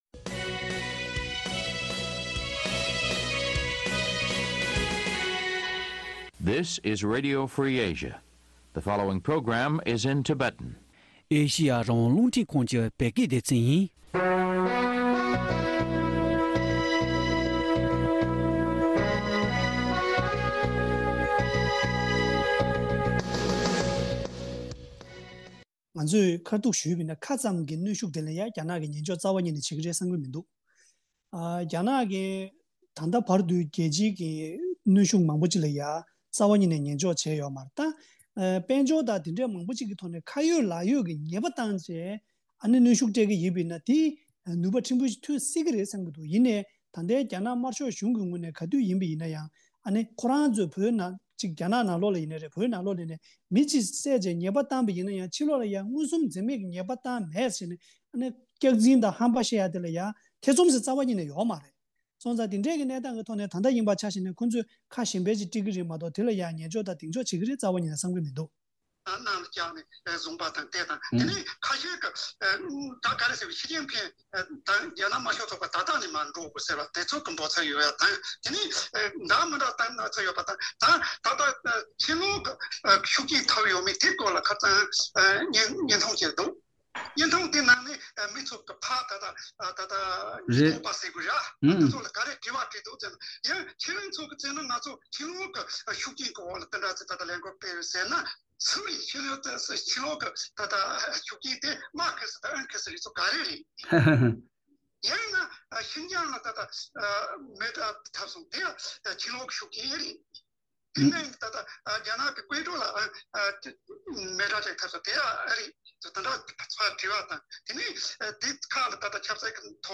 གླེང་མོལ་བྱས་པ་ གསན་ རོགས་ཞུ།